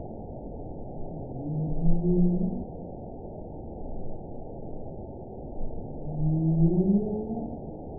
event 910399 date 01/21/22 time 11:41:46 GMT (3 years, 10 months ago) score 6.17 location TSS-AB08 detected by nrw target species NRW annotations +NRW Spectrogram: Frequency (kHz) vs. Time (s) audio not available .wav